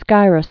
(skīrəs, skērôs)